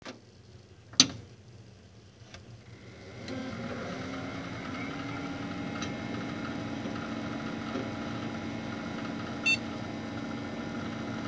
A normal startup beep sounds like this
Good-Memory-POST-Beep.wav